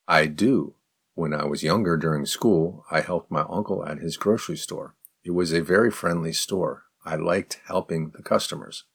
03_advanced_response_slow.mp3